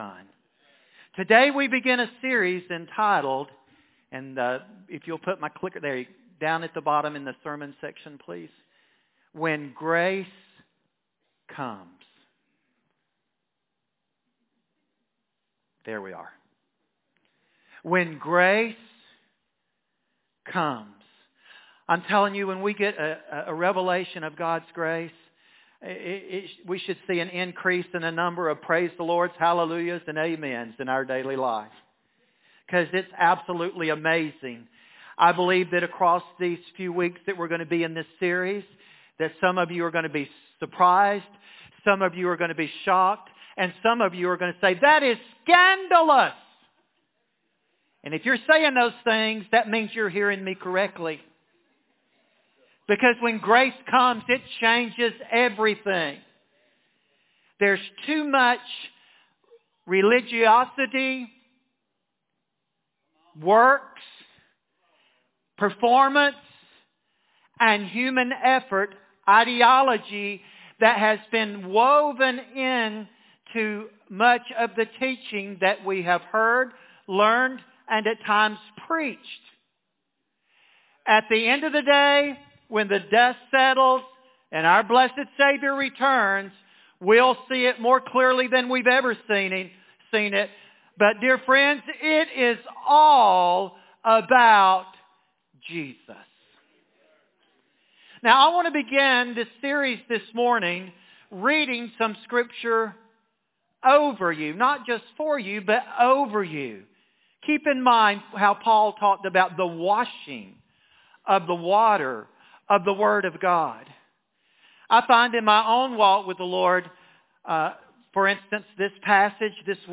Sermons & Messages